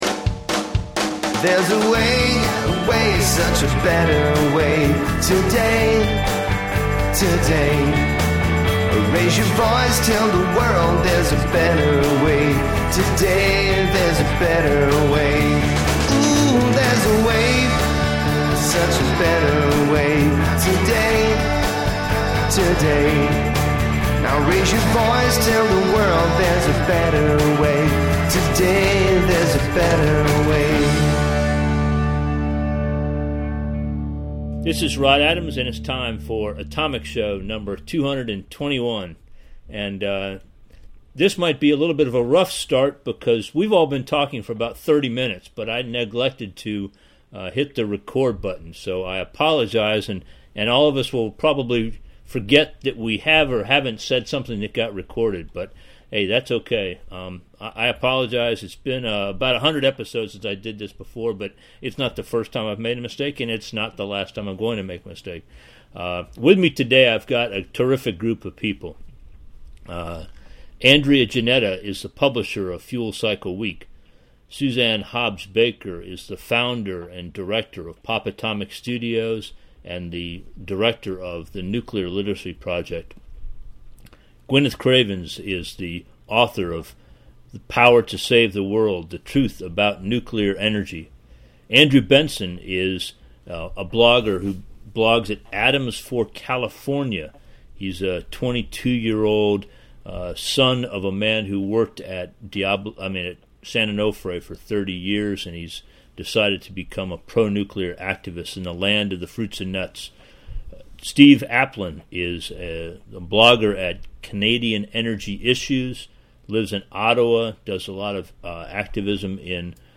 On August 25, 2014, a group of atomic energy advocates gathered to share experiences and advice about how nuclear energy advocates can more effectively act locally. We discussed ways to find people who are interested in atomic energy, ways to develop social interaction, ways to show our humanity, and ways to make it fun to support something beneficial to the world’s current population and future generations.